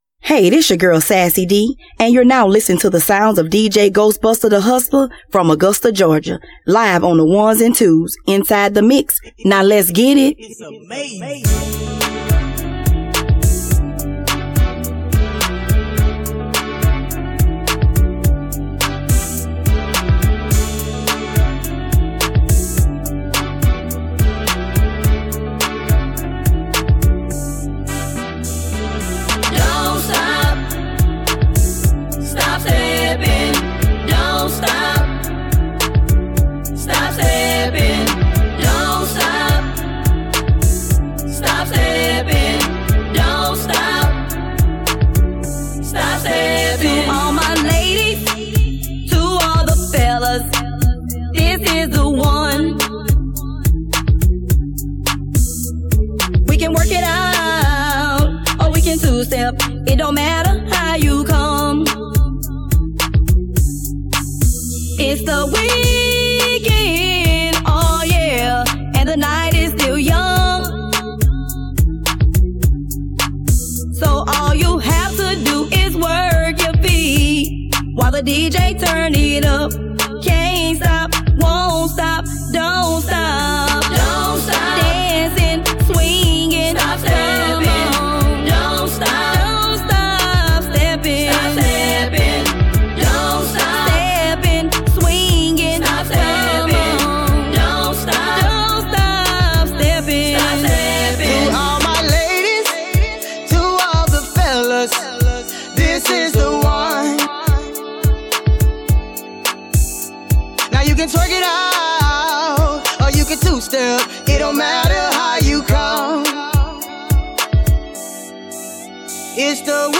It's A WorkOut Mix